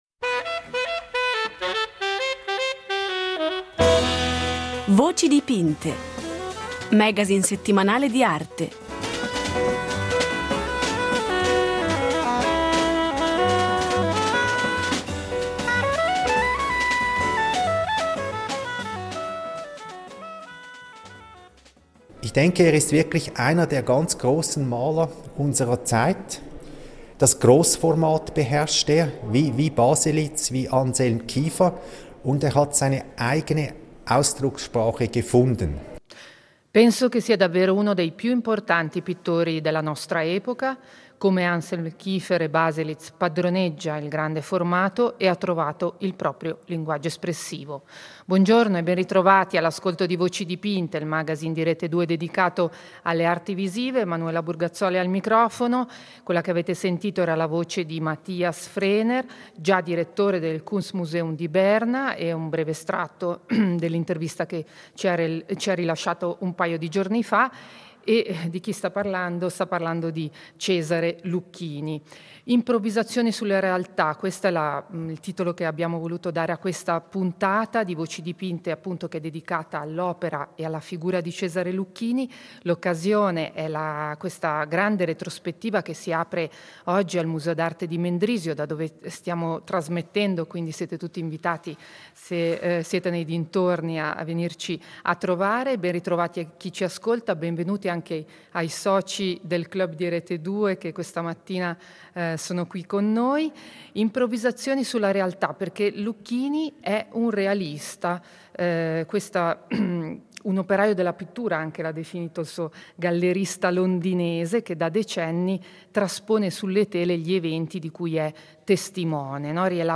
in diretta dal Museo